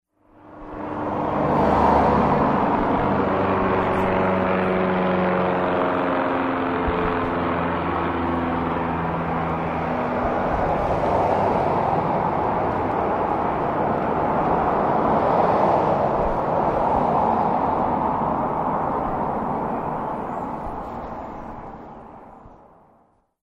The Environmental Sounds of Crawford, Texas
flyBy.mp3